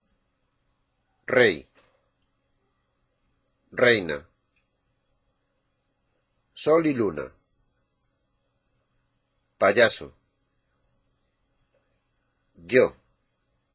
Listen to the following words which contain the letters "i" (called i latina in Spanish) and "y" (called i griega in Spanish)